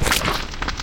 PixelPerfectionCE/assets/minecraft/sounds/mob/magmacube/small2.ogg at mc116